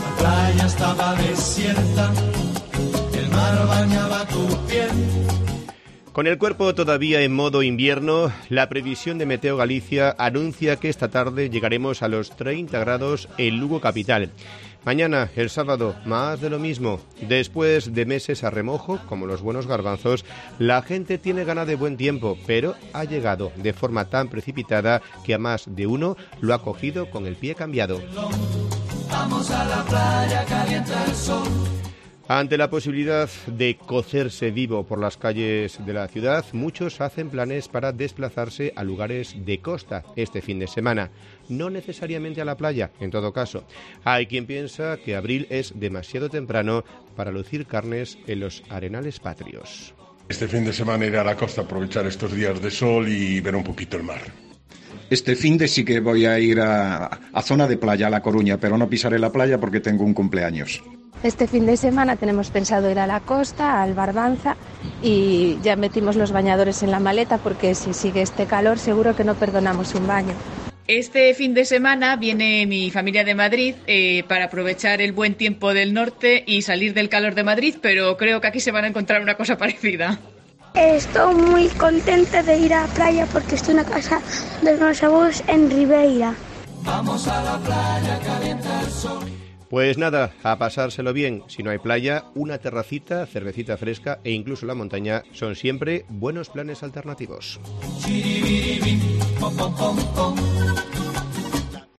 Sacamos los micrófonos de COPE Lugo a la calle para preguntarle a la gente que tiene pensado hacer este fin de semana.